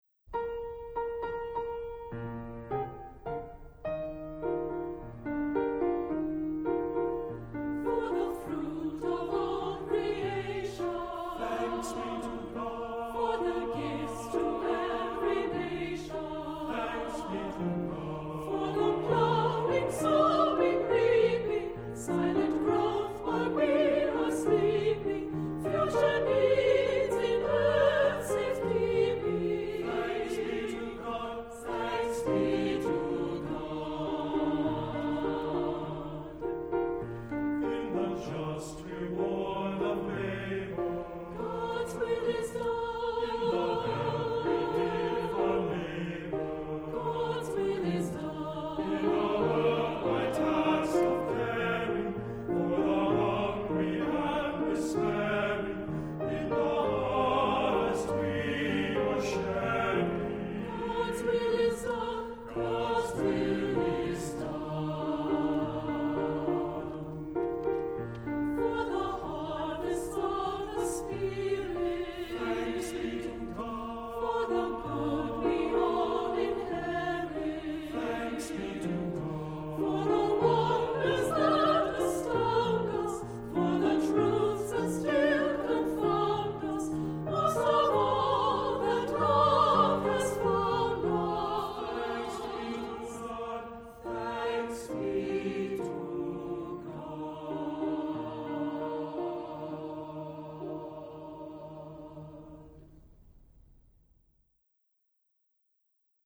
cheerful, flowing thanksgiving anthem for 2-part voices